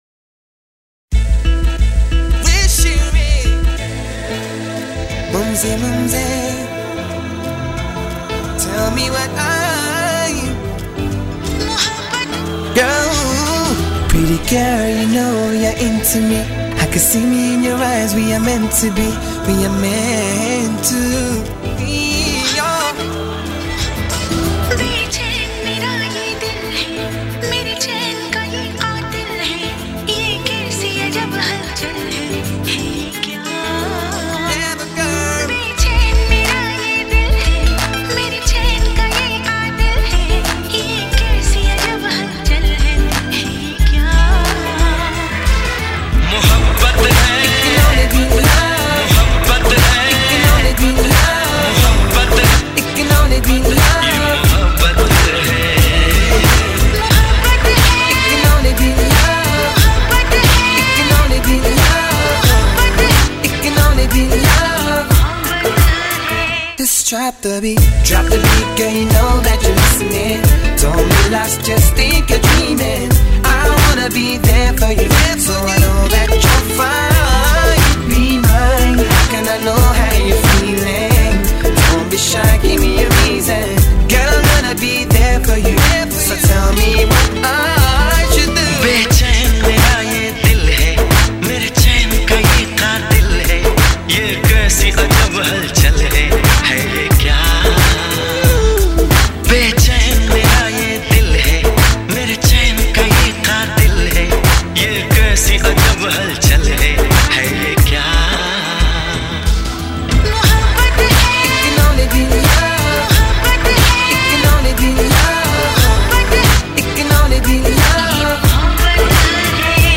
Hip Hop Remix